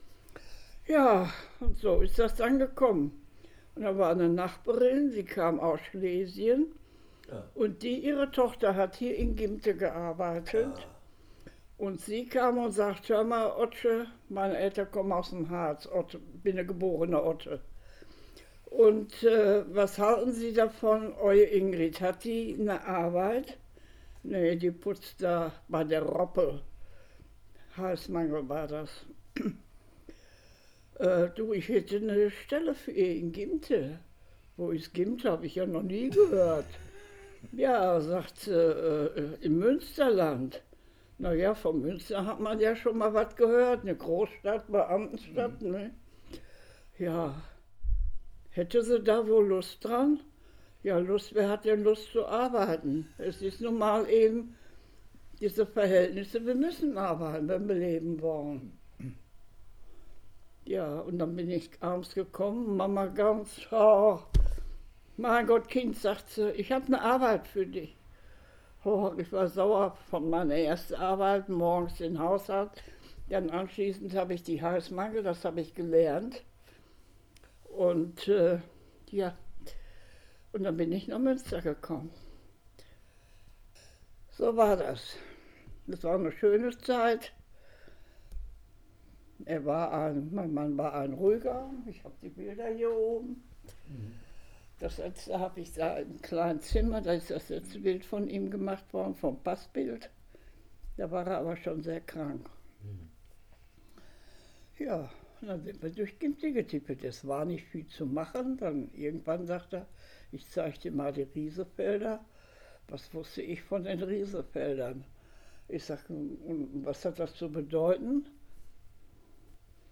Auszüge aus dem Interview: